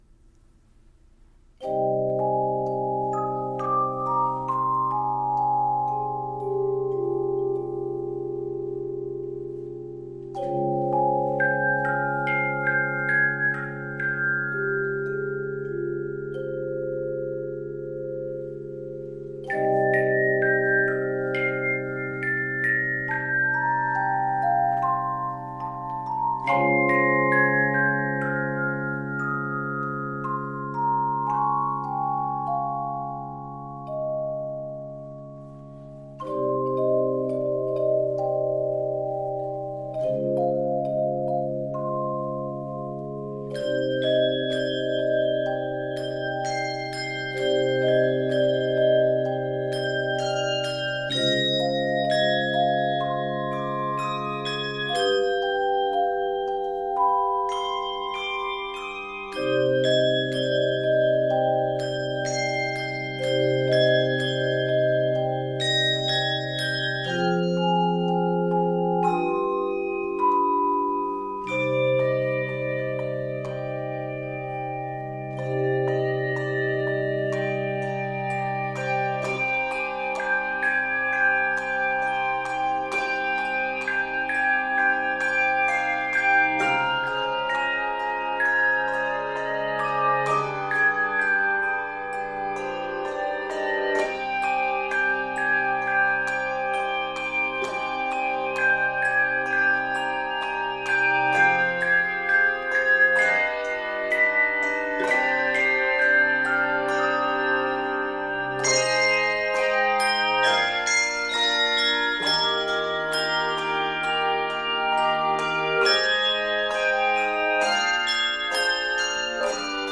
Elegant, expressive, and lyrical
Key of c minor.